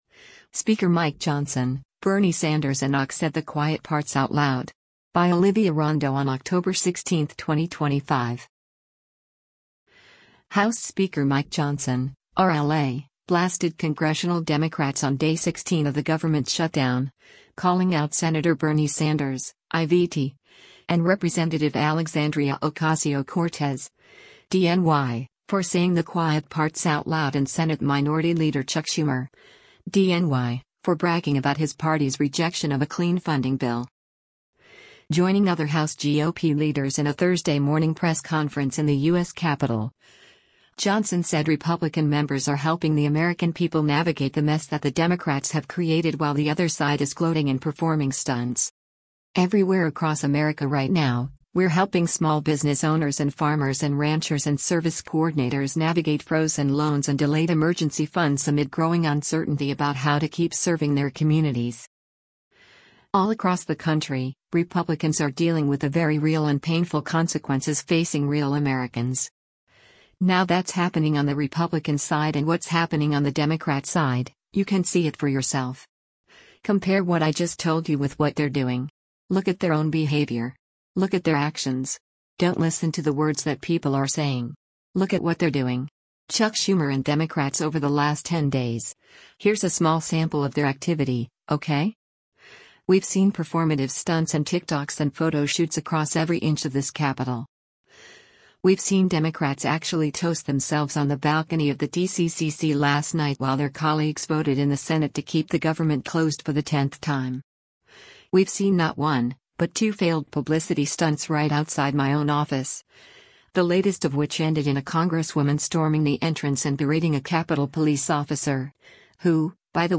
Joining other House GOP leaders in a Thursday morning press conference in the U.S. Capitol, Johnson said Republican members are “helping the American people navigate the mess that the Democrats have created” while the other side is gloating and performing “stunts.”